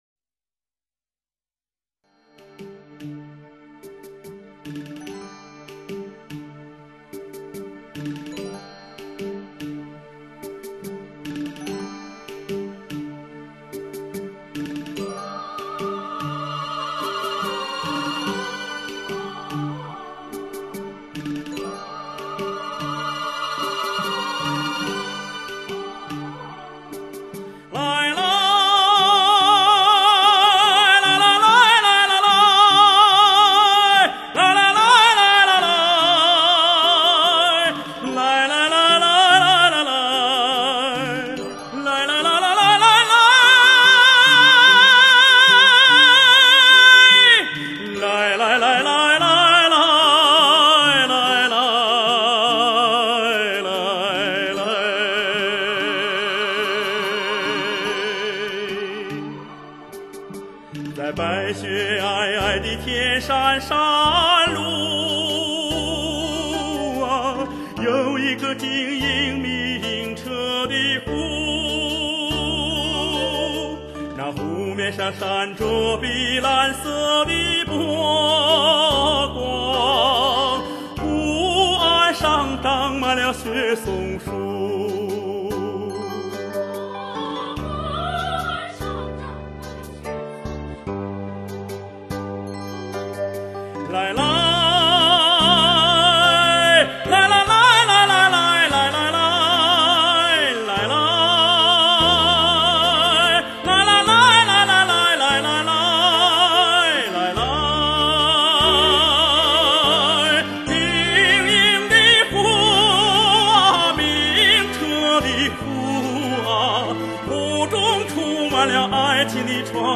·所属分类：音乐:民族声乐